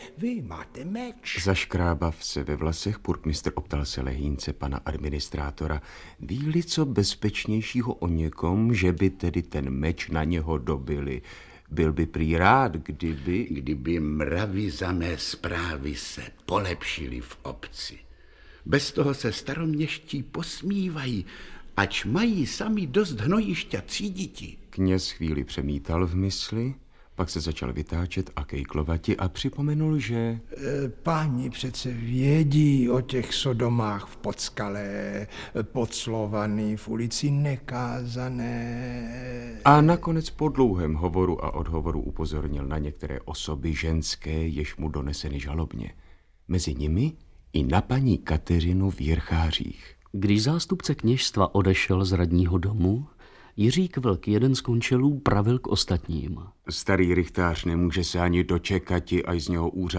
Audiobook
Read: Růžena Lysenková